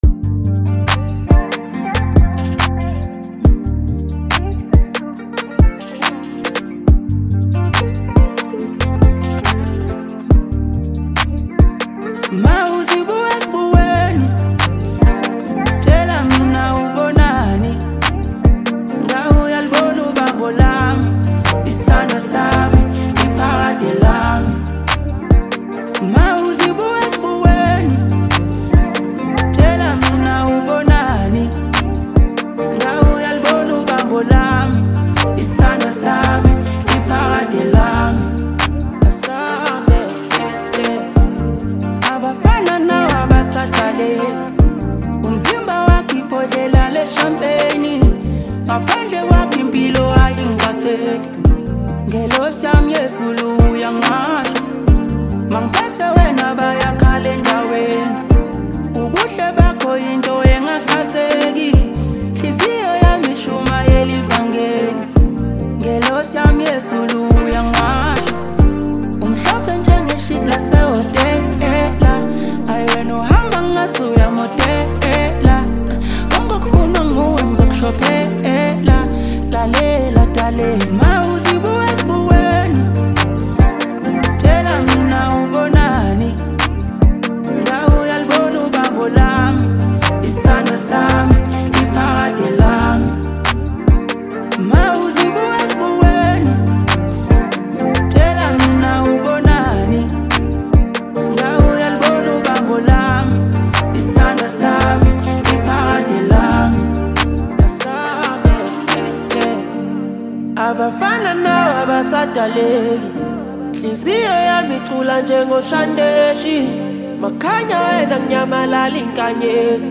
Home » Amapiano » DJ Mix » Hip Hop
South African singer-songwriter